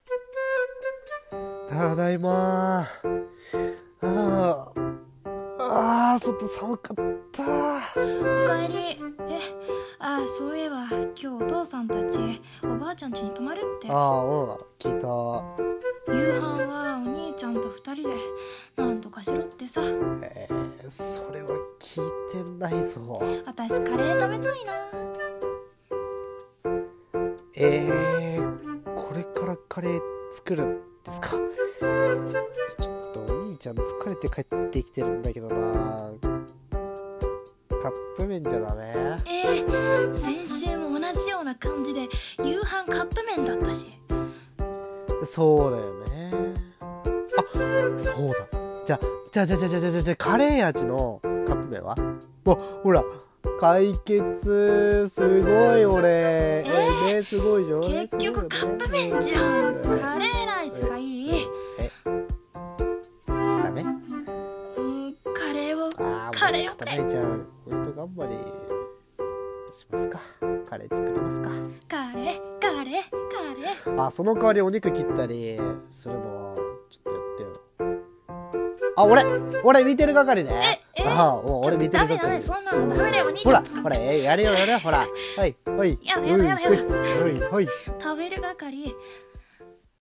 【声劇台本】カレーがいい